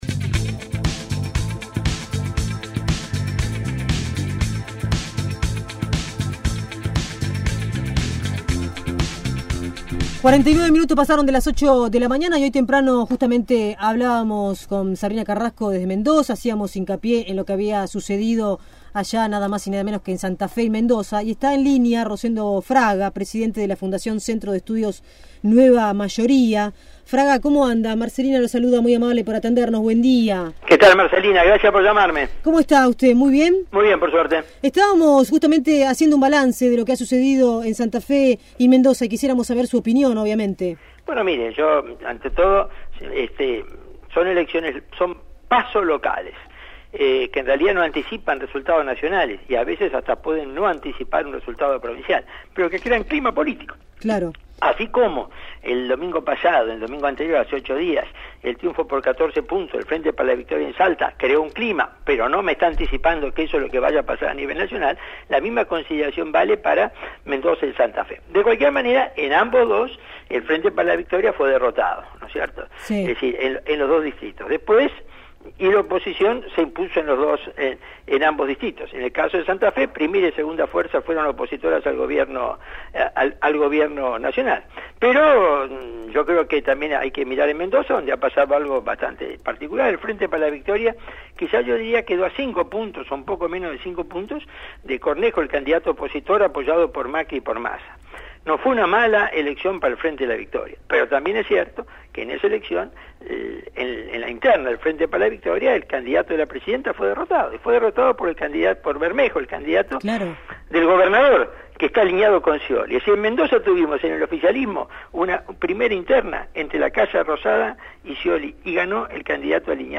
El Director del Centro de Estudios Nueva Mayoría, Rosendo Fraga, dialogó